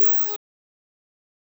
synth3 (2).wav